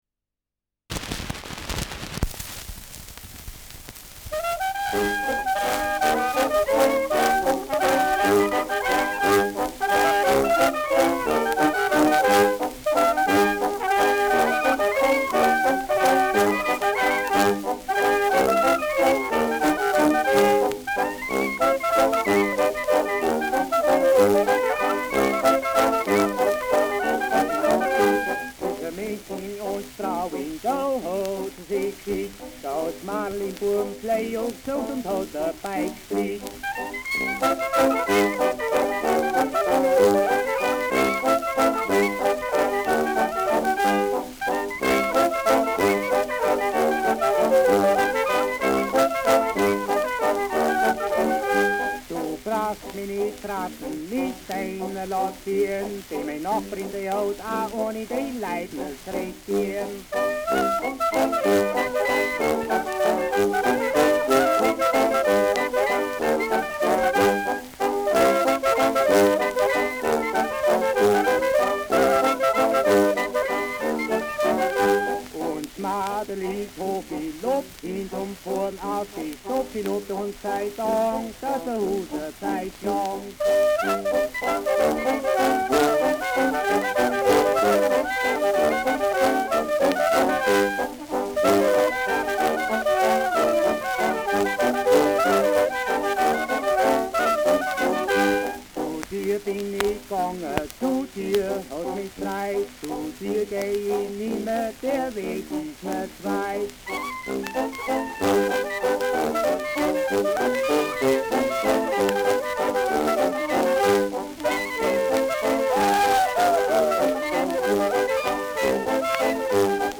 Ländler mit Gesang
Schellackplatte